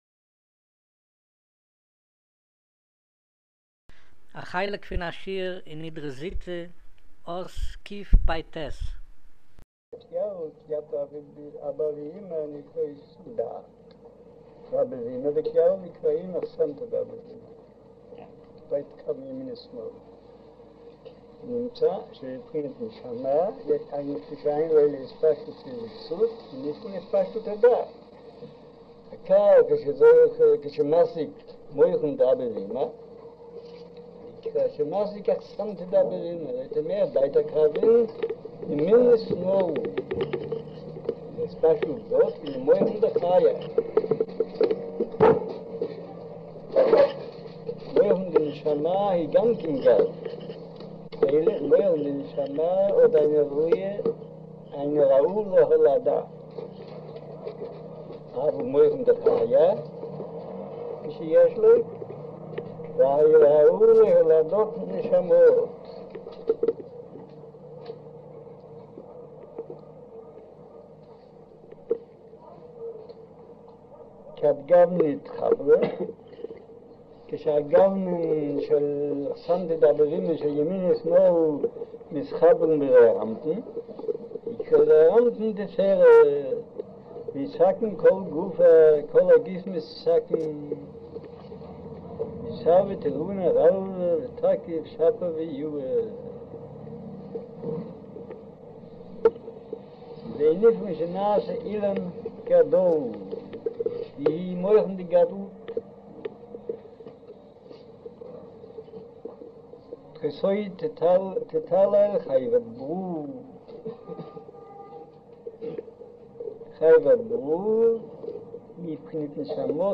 אודיו - שיעור מבעל הסולם